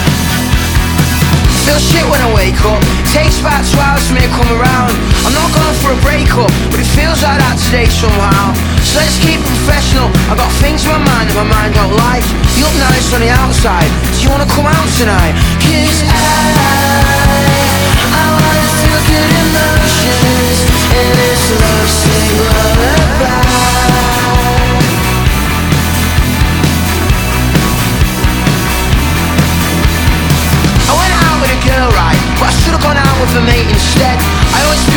2025-04-25 Жанр: Альтернатива Длительность